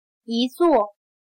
一座/yīzuò/Un asiento